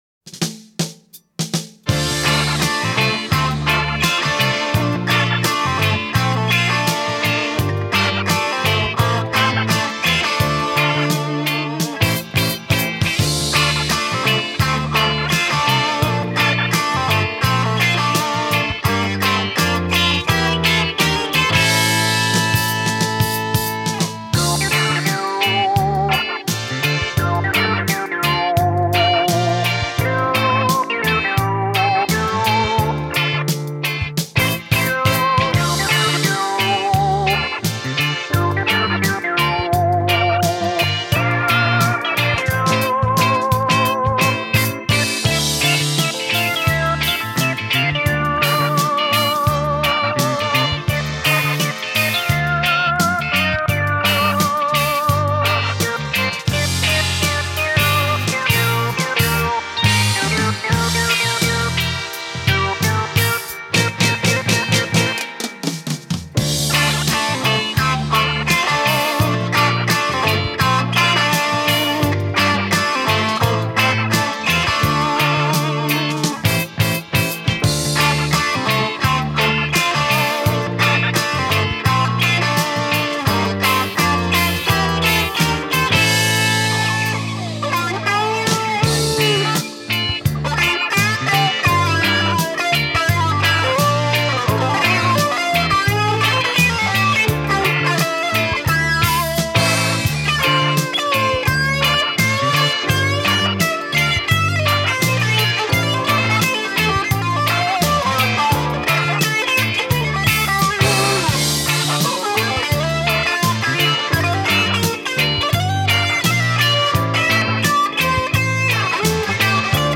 Populārā mūzika
Instrumentāls skaņdarbs